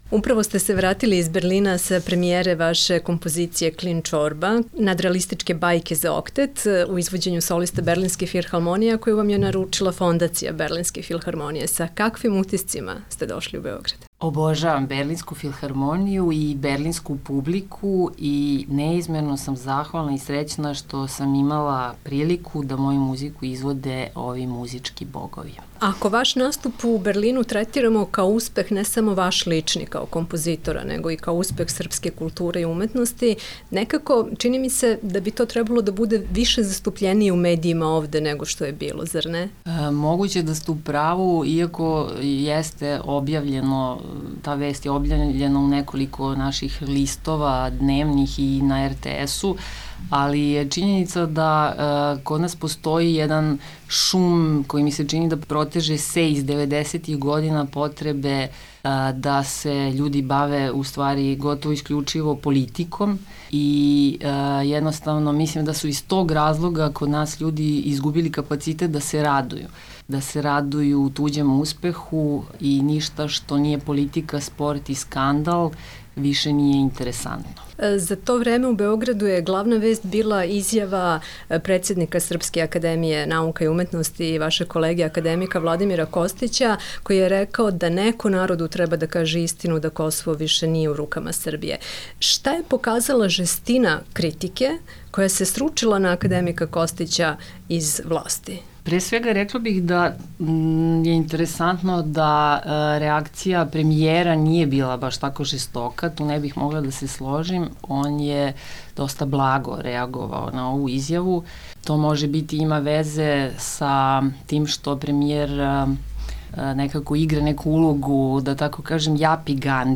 Intervju nedelje: Isidora Žebeljan